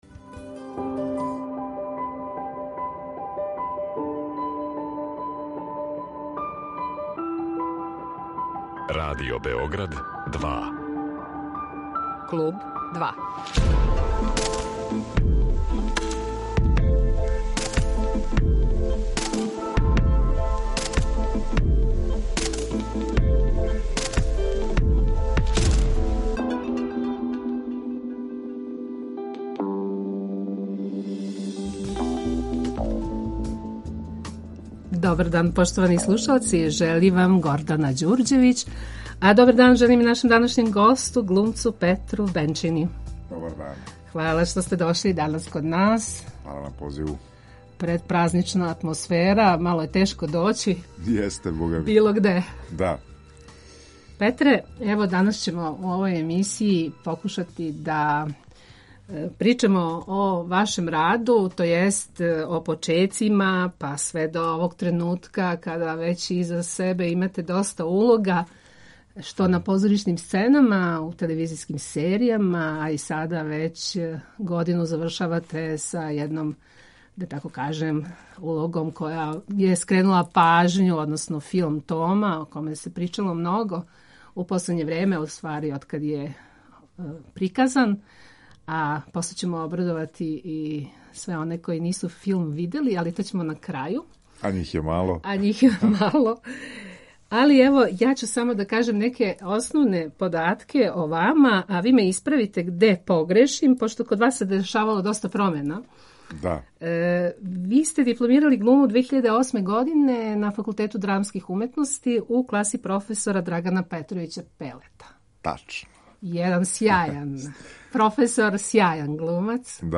Гост Kлуба 2 је позоришни, телевизијски и филмски глумац Петар Бенчина